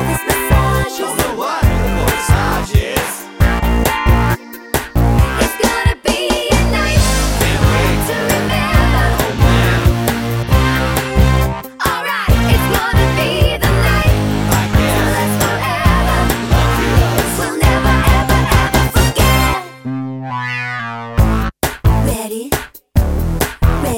No Backing Vocals Soundtracks 3:56 Buy £1.50